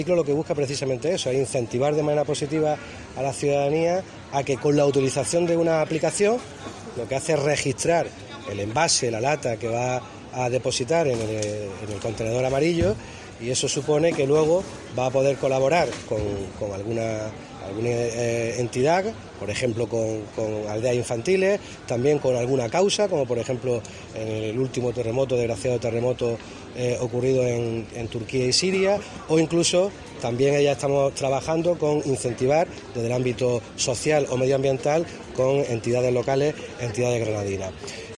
Jacobo Calvo, concejal de medioambiente